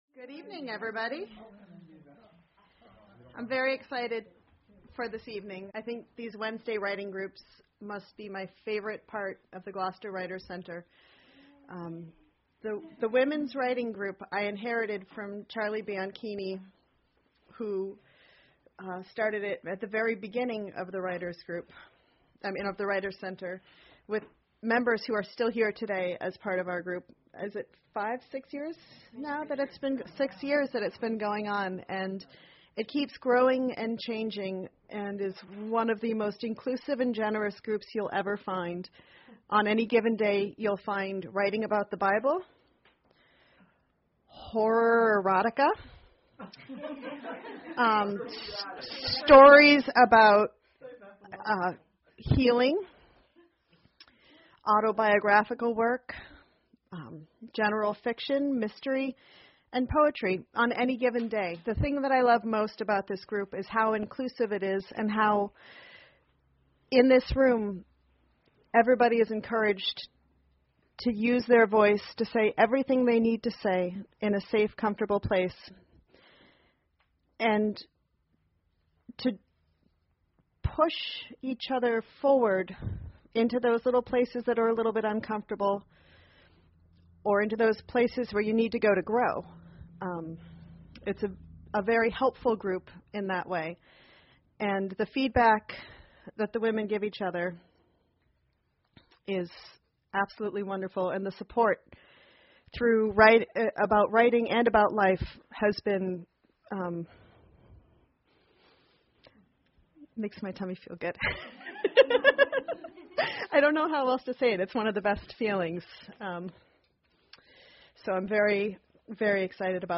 In Our Words: A Reading of the Wednesday Writing Group
words-reading-wednesday-writing-group